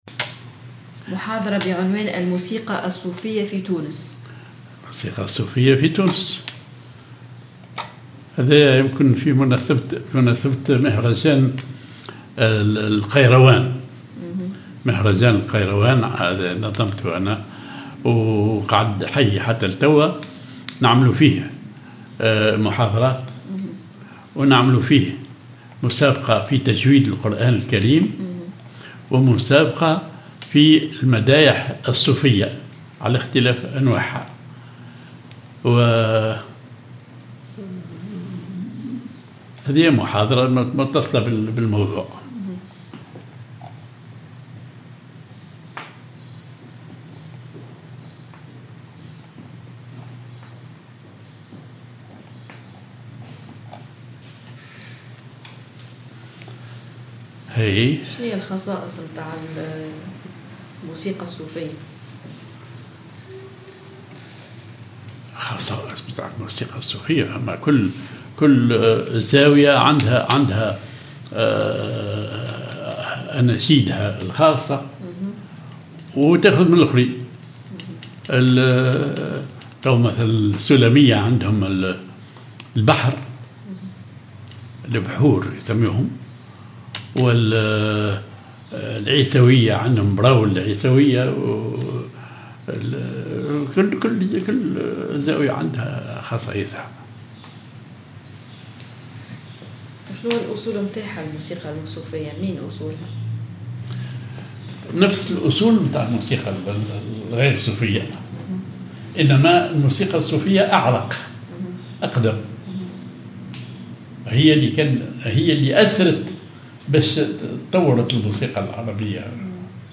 Musique soufie en Tunisie